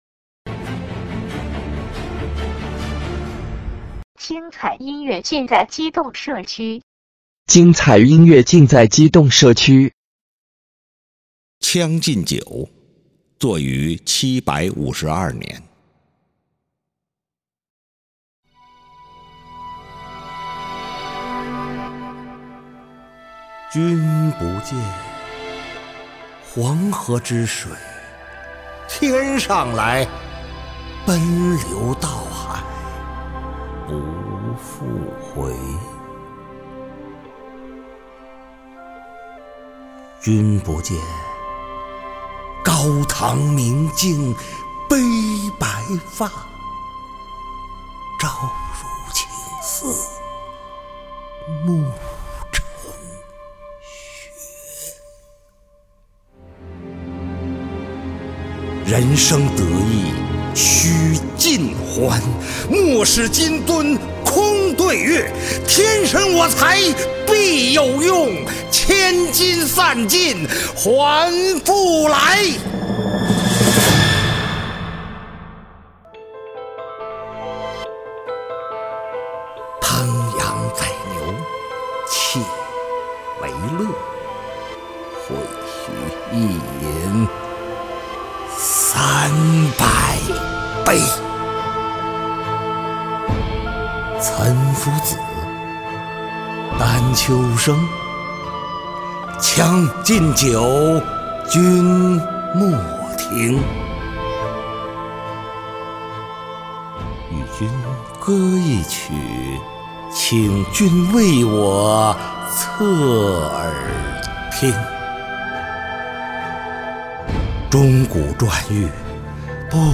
激扬沉郁，悲怆苍晾